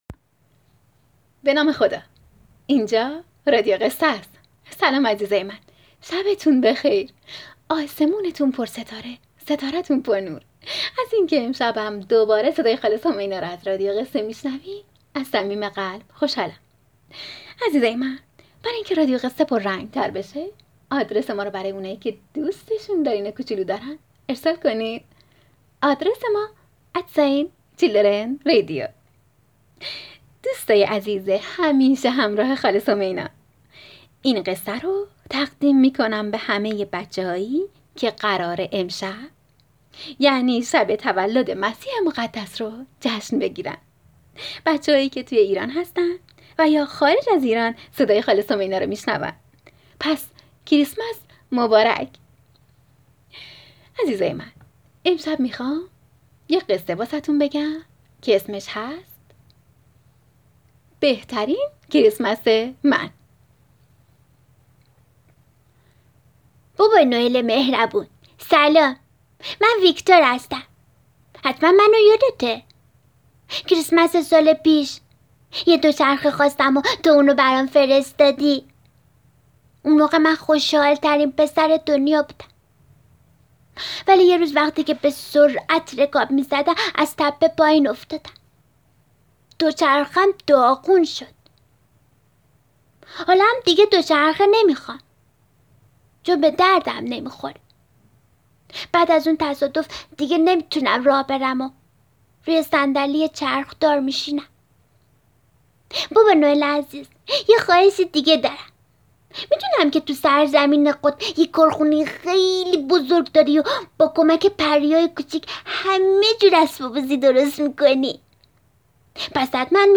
قصه صوتی کودکانه بهترین کریسمس من